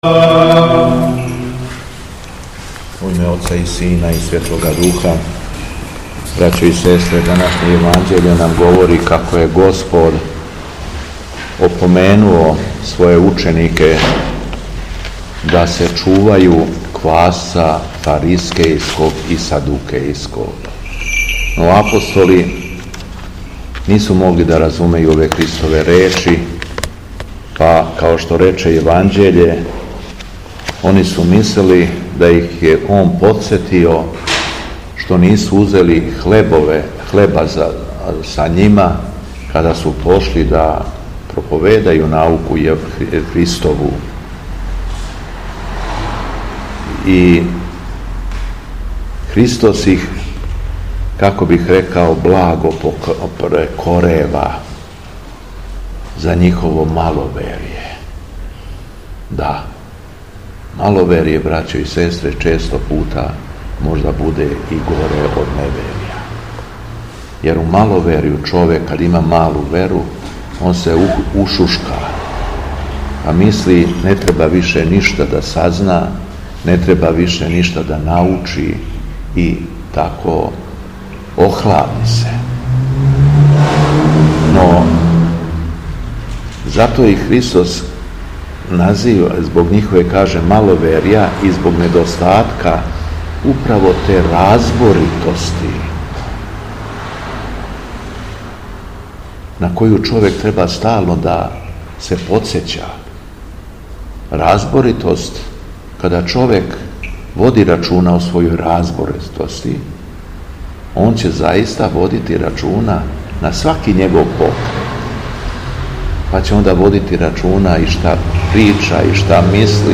У уторак, 29. јула 2025. године, када наша света Црква прославља светог свештеномученика Антиногена и свету мученицу Јулију, Његово Високопреосвештенство Митрополит шумадијски Господин Јован служио је Свету архијерејску Литургију у крагујевачком насељу Сушица, у храму Светог великомученика Димитрија...
Беседа Његовог Високопреосвештенства Митрополита шумадијског г. Јована
После прочитаног Јеванђеља Високопреосвећени Владика се обратио верном народу беседом рекавши: